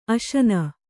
♪ aśana